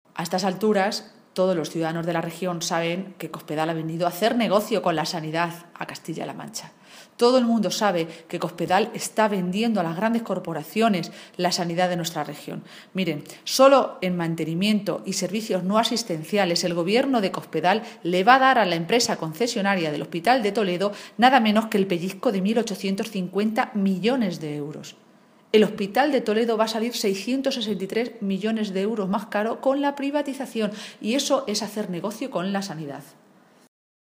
Maestre se pronunciaba de esta manera esta mañana, en Toledo, en una comparecencia ante los medios de comunicación en la que, en primer lugar, resaltaba la incompetencia de un Gobierno “que lleva con las obras del Hospital paradas más de 1.000 días, más de tres años, y que ha dado hasta cinco fechas distintas para su reanudación”.
Cortes de audio de la rueda de prensa